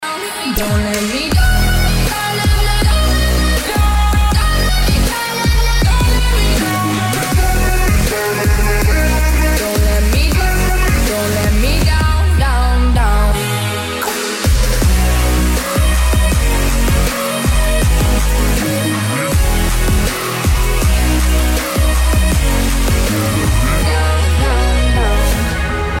из Клубные
Категория - клубные.